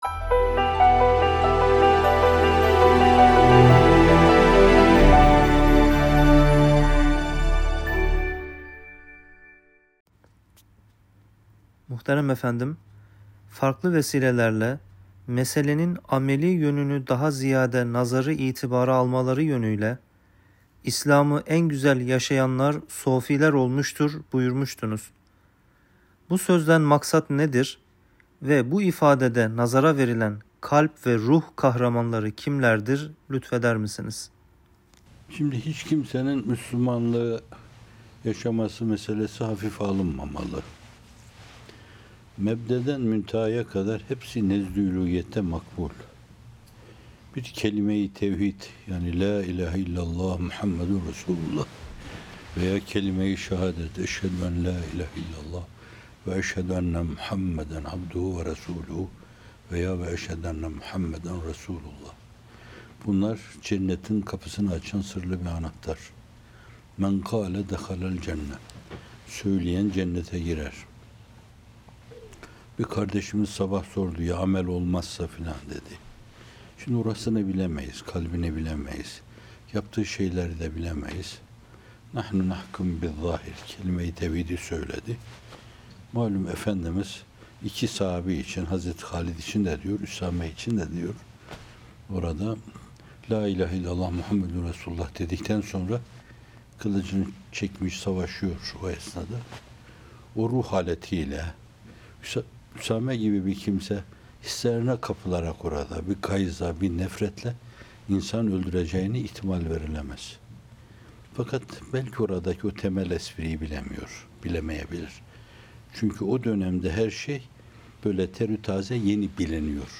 Bamteli Yeni – Herkes Kendine Bakmalı - Fethullah Gülen Hocaefendi'nin Sohbetleri